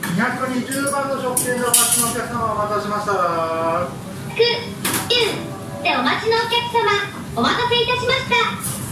ところで、導入間もないための一時的な状況だとは思いますが、新しいシステムの番号呼出し音声の品質が極めて悪いです。
従来システムの音声もイントネーションがおかしく、子供が変な発音を覚えてしまうのではないかと心配しましたが、今度のものは、番号がまったく聞き取れないくらいひどいものです。
最初に店員さんの呼出しが聞こえ、続いて機械の音声が続きます。